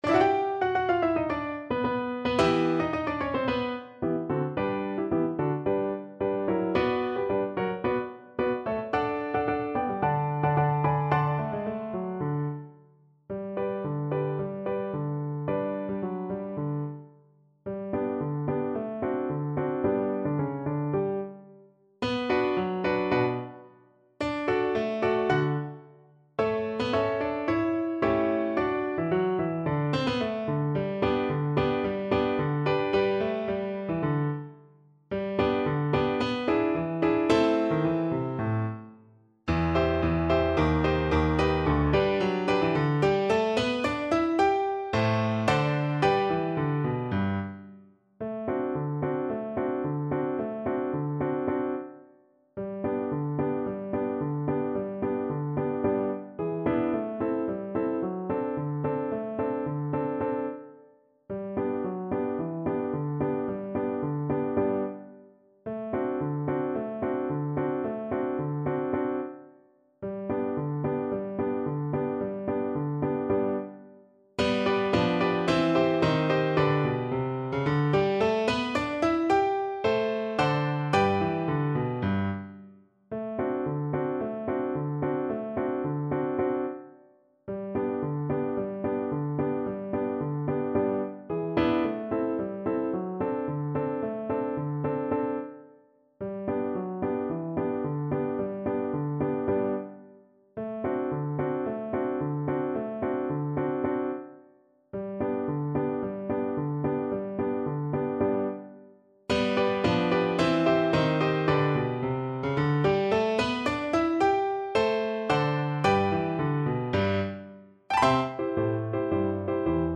Classical (View more Classical Viola Music)